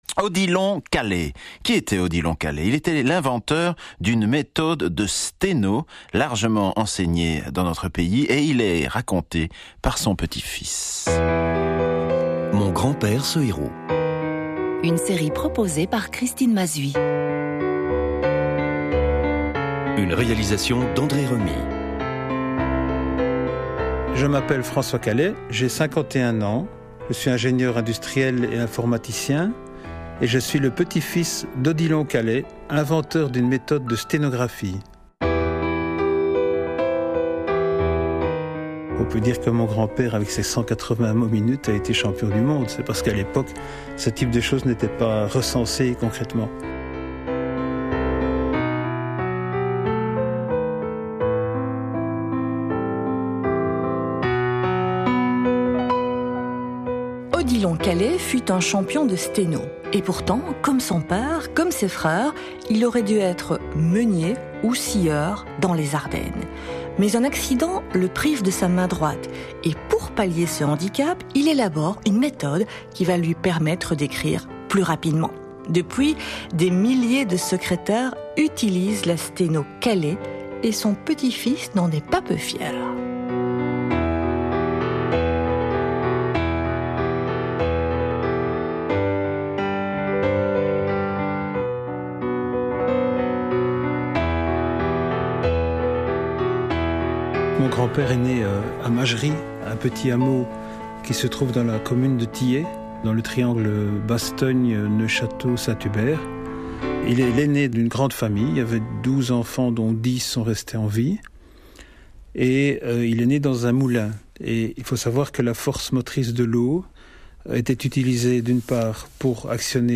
Si vous désirez écouter l'émission radio qui lui a été consacrée à la RTBF dans le cadre de "Mon grand père ce héros", veuillez cliquer sur le lien suivant :